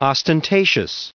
555_ostentatious.ogg